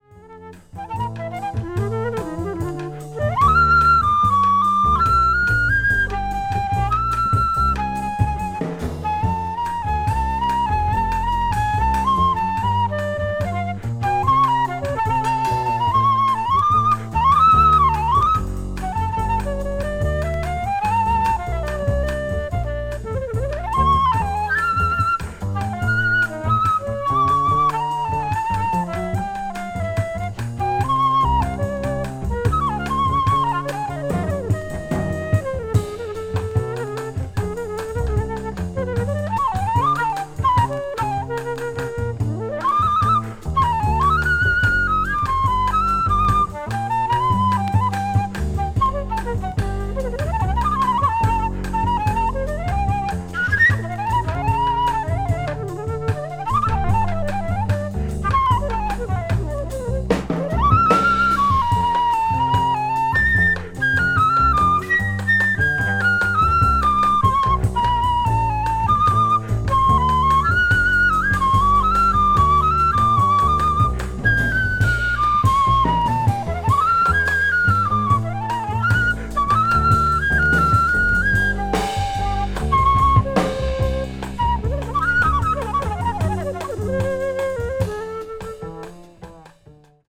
bossa jazz   hard bop   modern jazz   post bop